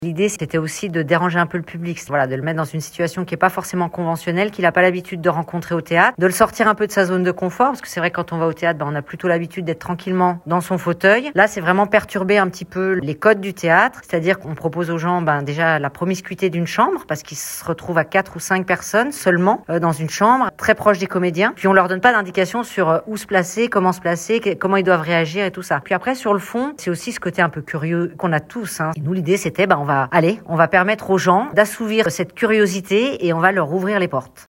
était au micro d'ODS radio.